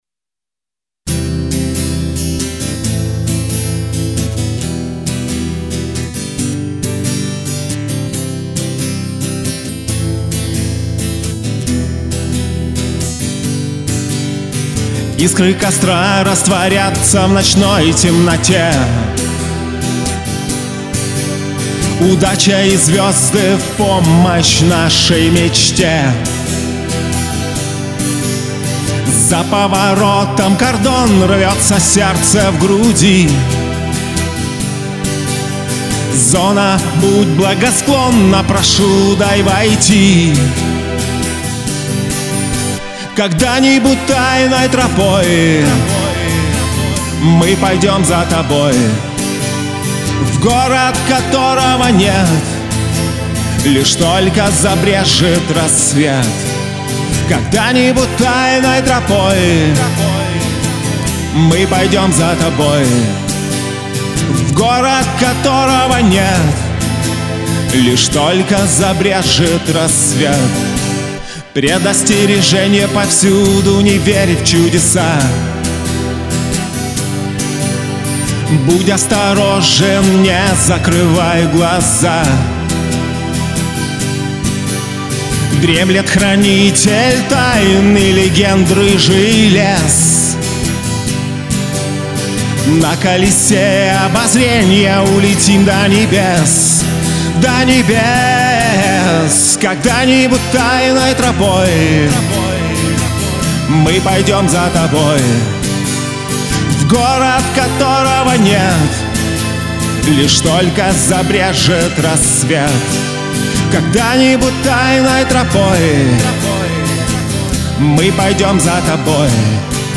acoustic 2017